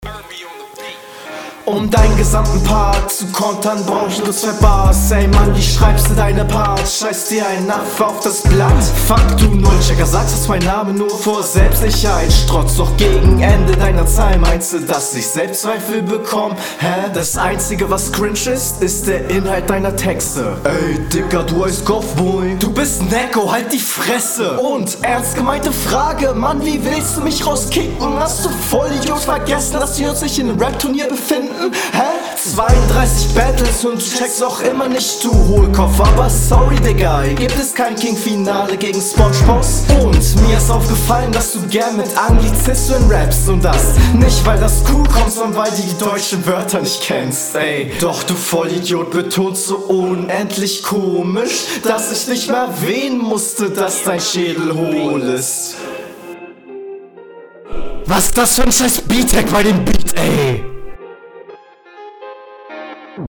Der Flow, der in dieser Runde größtenteils Halftime gehalten ist, gefällt mir auf diesem Beat …